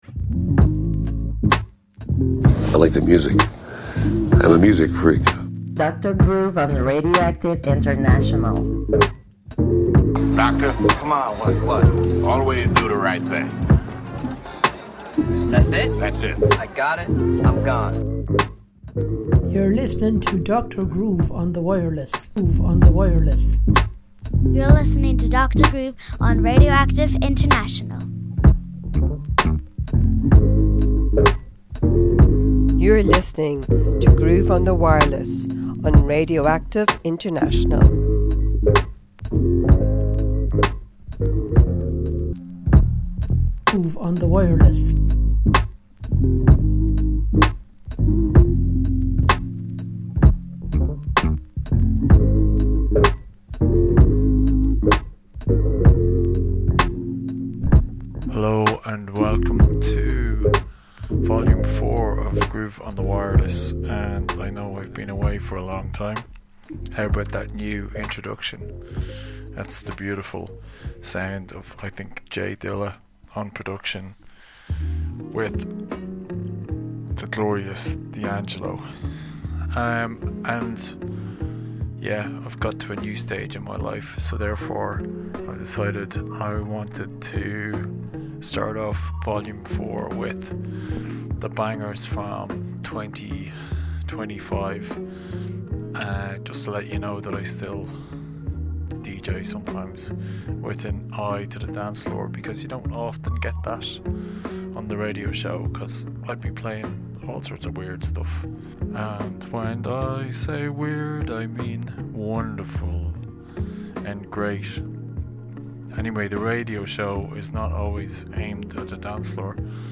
We are back with the bangers from 2025.